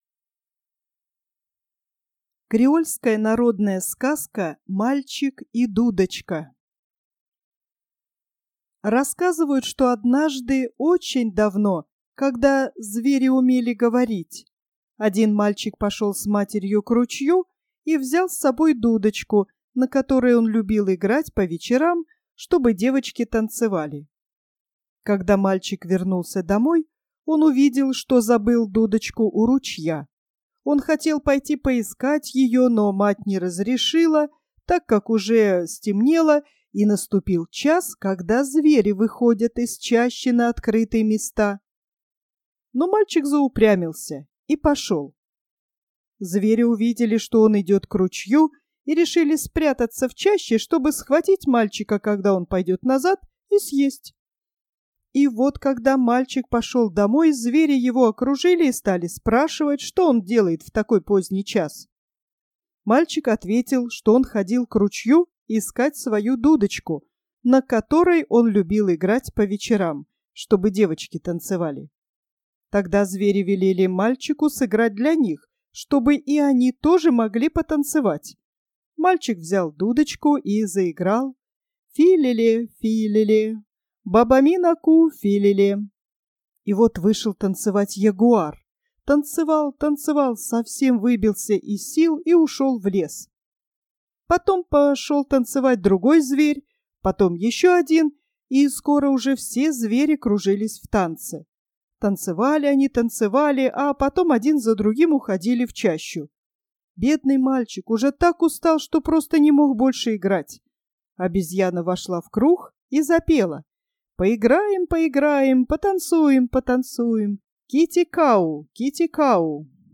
Креольская народная короткая аудио сказка о волшебной дудочке "Мальчик и дудочка", перевод И. Тертерян, "Сказки народов мира", том V, стр. 604 - 605.